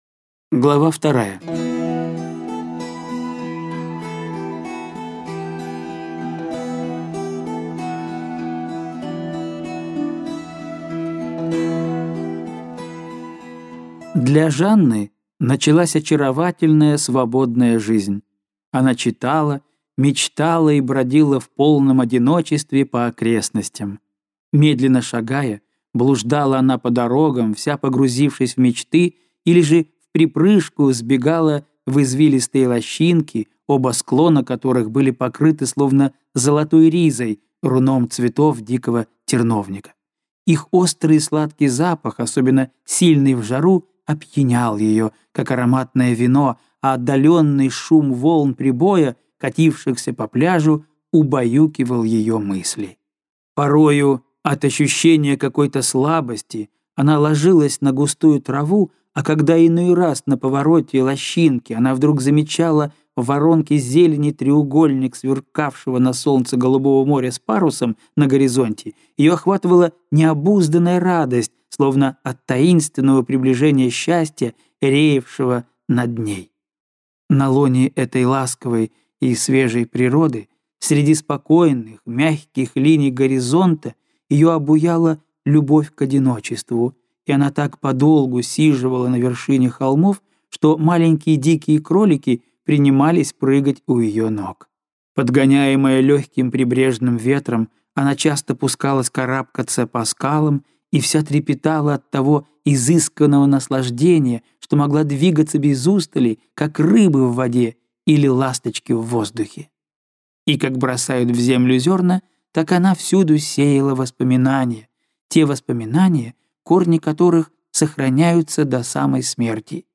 Аудиокнига Жизнь. Новеллы | Библиотека аудиокниг